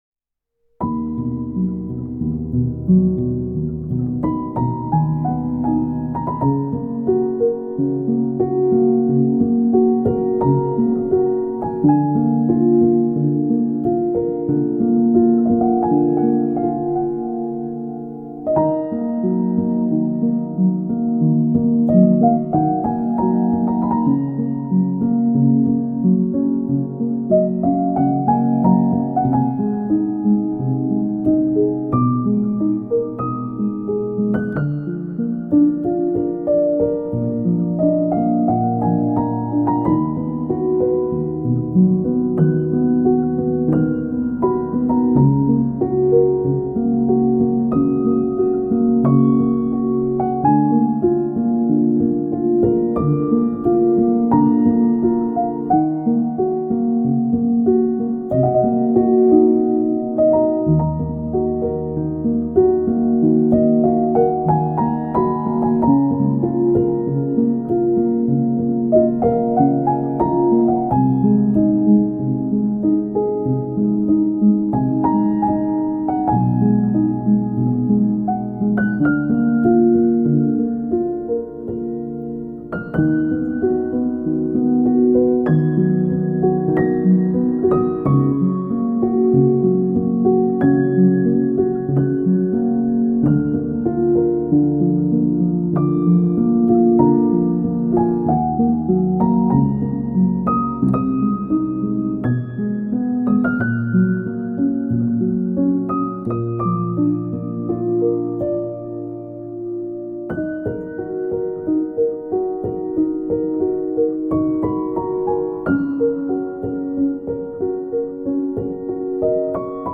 پیانو
آرامش بخش الهام‌بخش پیانو مدرن کلاسیک موسیقی بی کلام